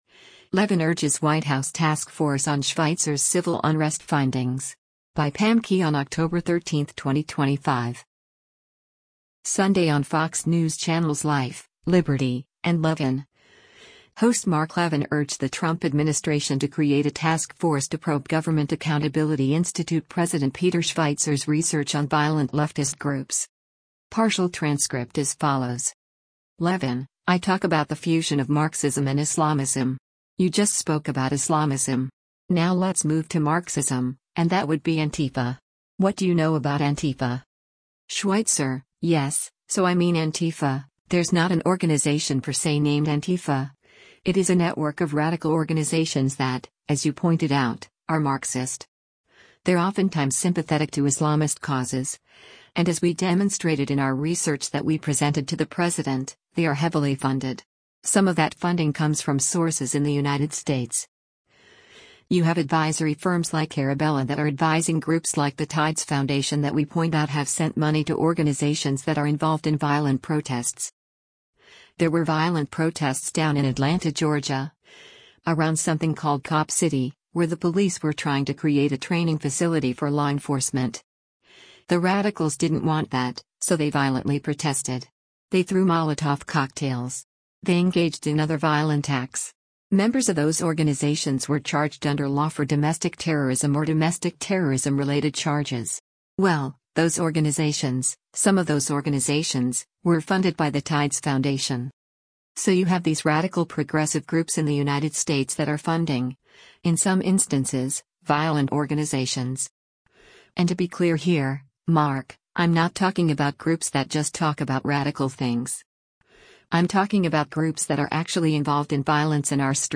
Sunday on Fox News Channel’s “Life, Liberty & Levin,” host Mark Levin urged the Trump administration to create a task force to probe Government Accountability Institute president Peter Schweizer’s research on violent leftist groups.